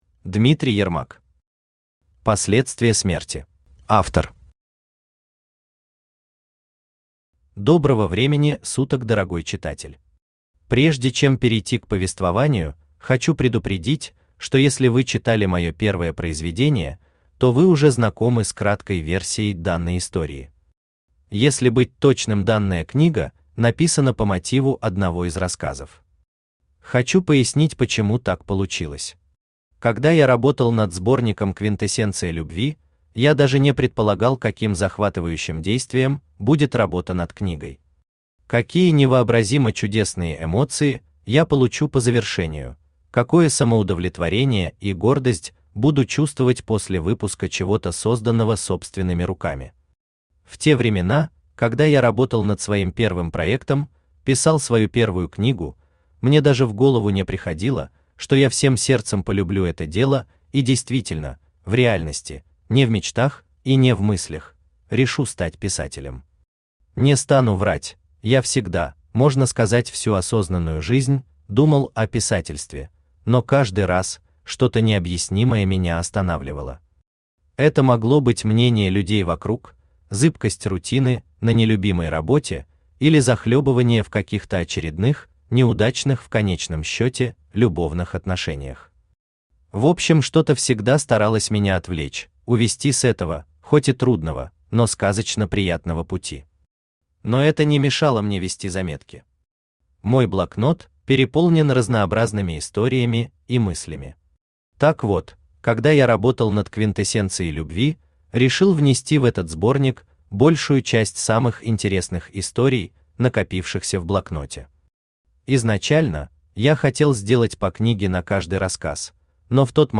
Аудиокнига Последствия смерти | Библиотека аудиокниг
Aудиокнига Последствия смерти Автор Дмитрий Ермак Читает аудиокнигу Авточтец ЛитРес.